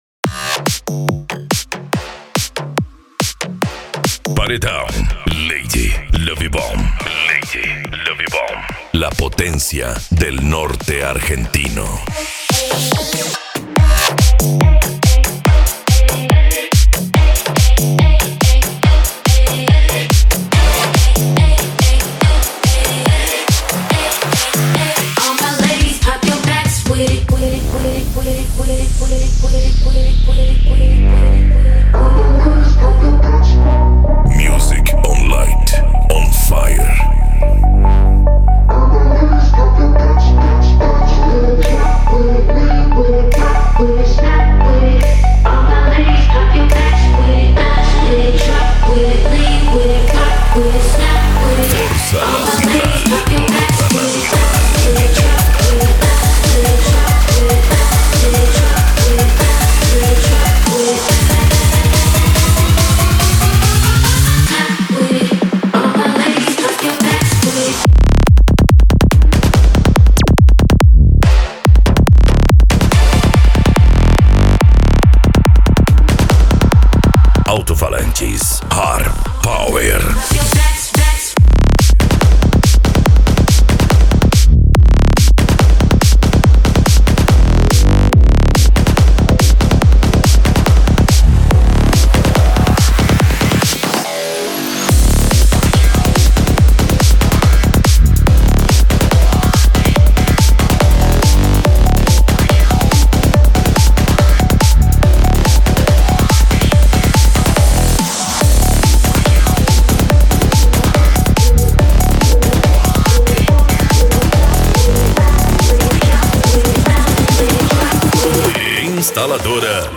Bass
Eletronica
Remix